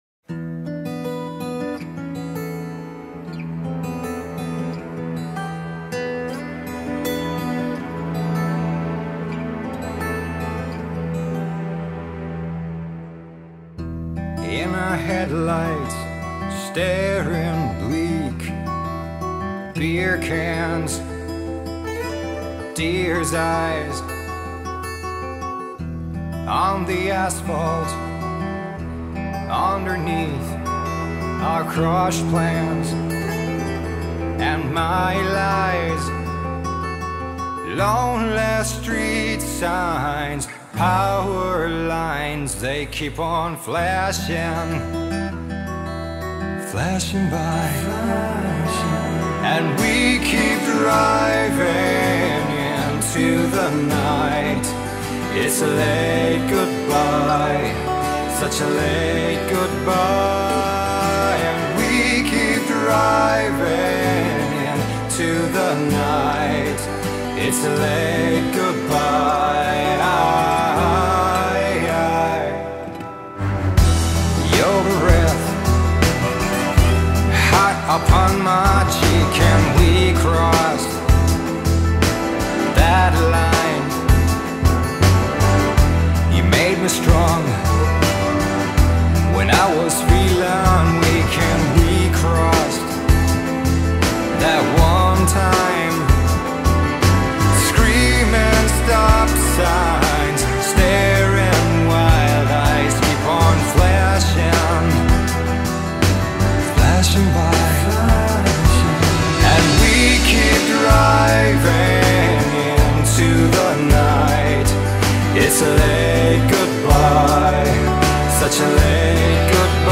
گروه راک فنلاندی